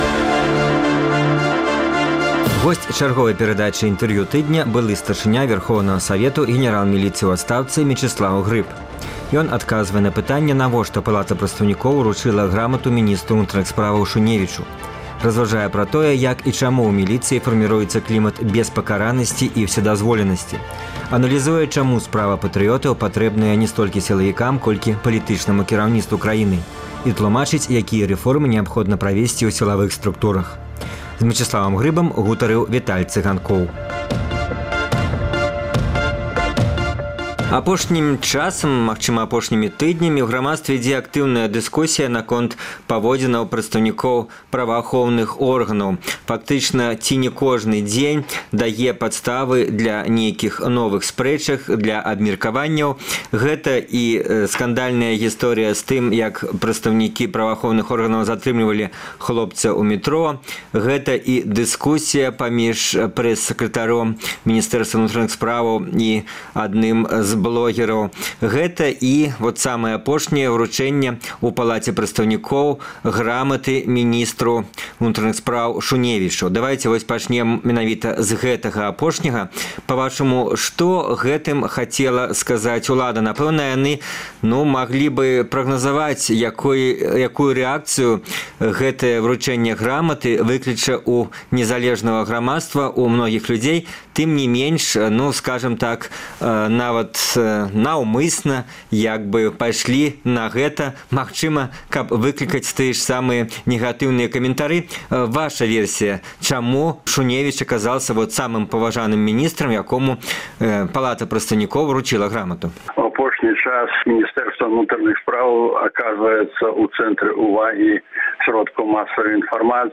Госьць чарговай перадачы “Інтэрвію тыдня” Былы старшыня Вярхоўнага Савету, генэрал міліцыі ў адстаўцы Мечыслаў Грыб. Ён адказвае на пытаньне, навошта Палата прадстаўнікоў уручыла грамату міністру ўнутраных справаў Шуневічу, разважае пра тое, як і чаму ў міліцыі фарміруецца клімат беспакаранасьці і ўседазволенасьці, аналізуе, чаму “справа патрыётаў” патрэбная ня столькі сілавікам, колькі палітычнаму кіраўніцтву, і тлумачыць, якія рэформы неабходна правесьці ў сілавых структурах.